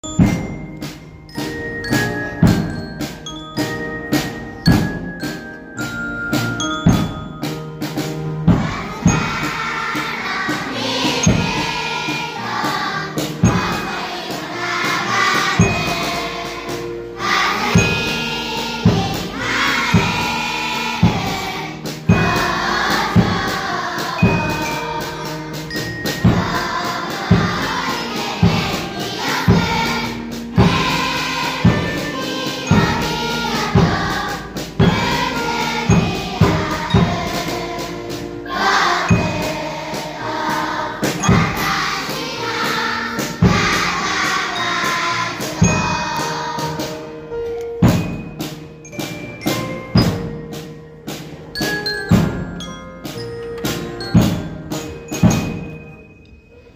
全校♪校歌1番♪6月30日